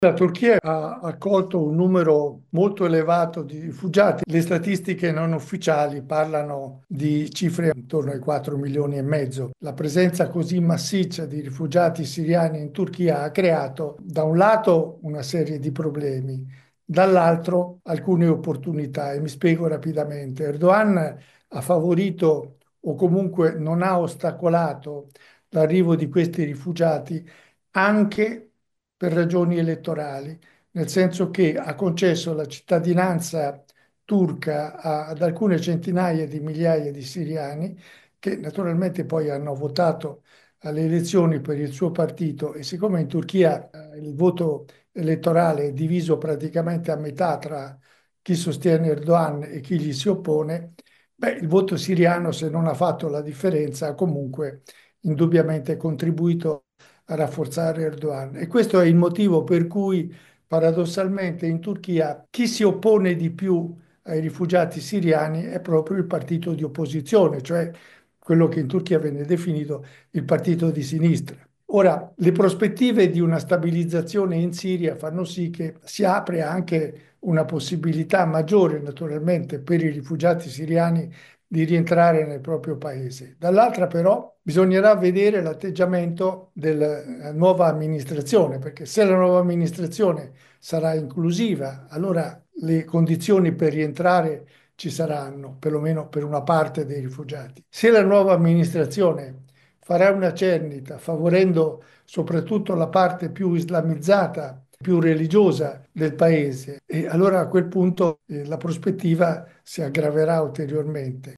Ascoltiamo Carlo Marsili, ambasciatore d’Italia in Turchia dal 2004 al 2010
sonoro-marsili.mp3